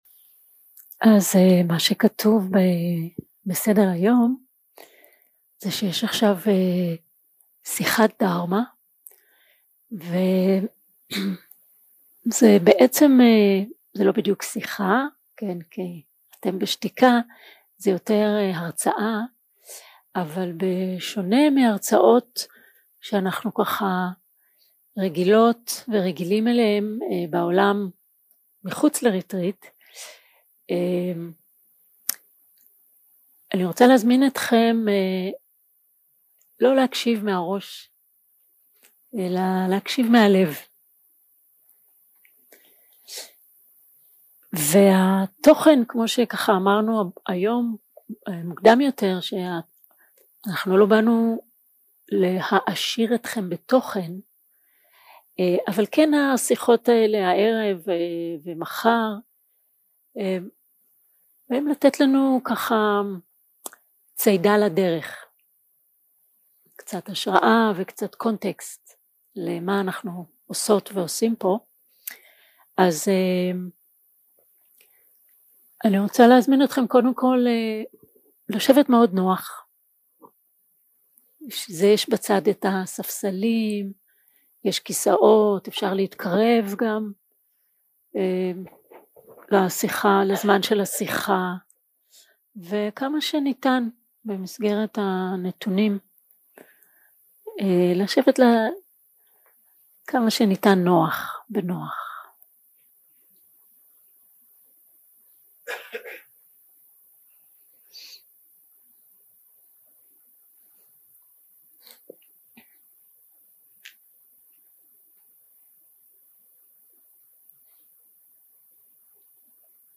יום 1 – הקלטה 1 – ערב – שיחת דהארמה – לראות את פני הבודהה
סוג ההקלטה: שיחות דהרמה